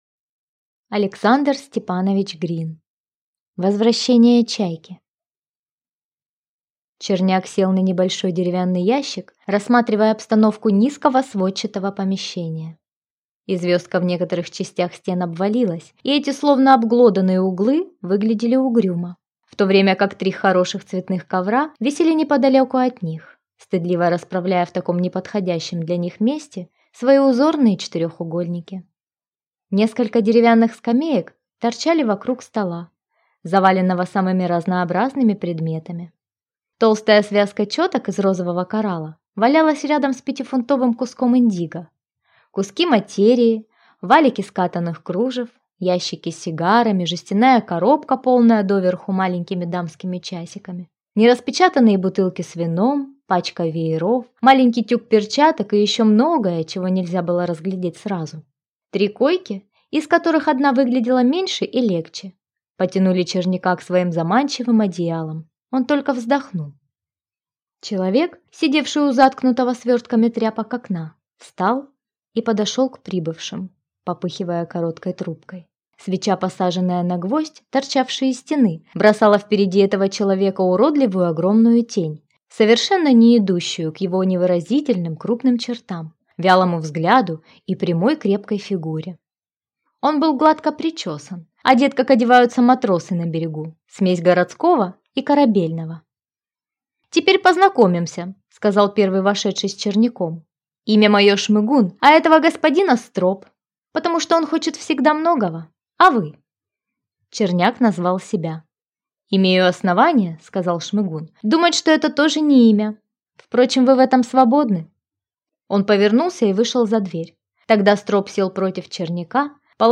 Аудиокнига Возвращение «Чайки» | Библиотека аудиокниг